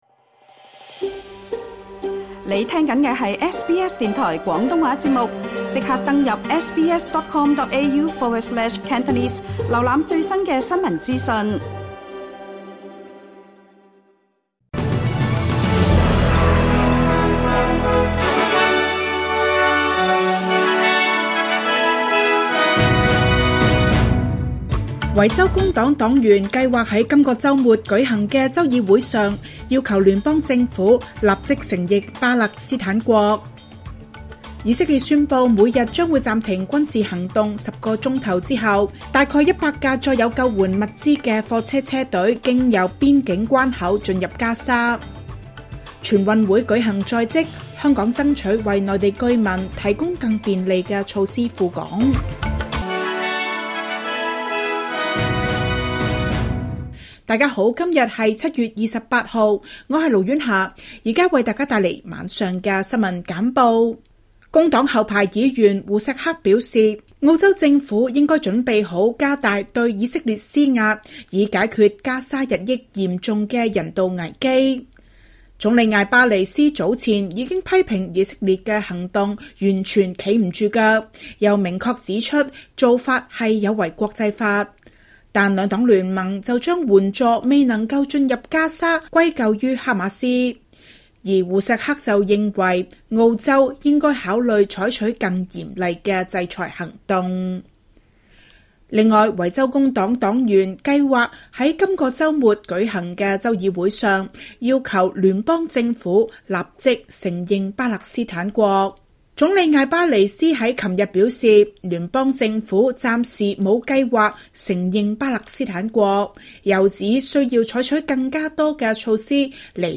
SBS晚間新聞（2025年7月28日）
請收聽本台為大家準備的每日重點新聞簡報。